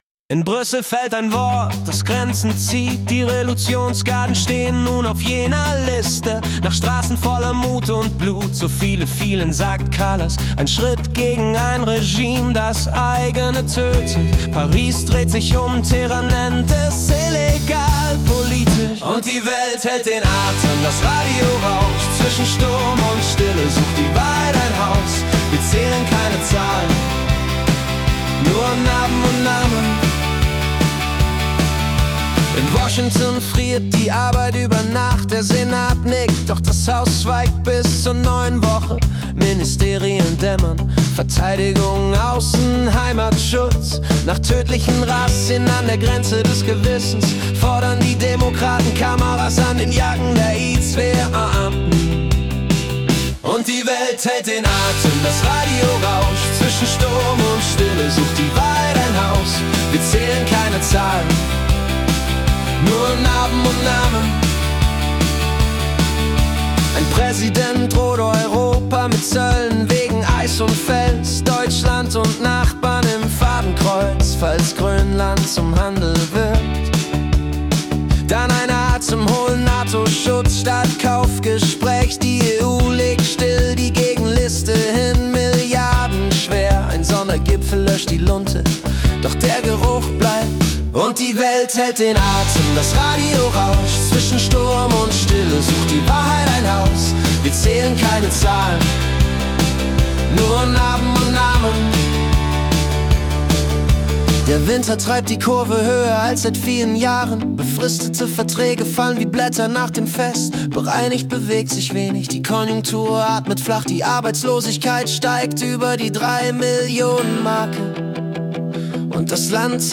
Die Nachrichten vom 1. Februar 2026 als Singer-Songwriter-Song interpretiert.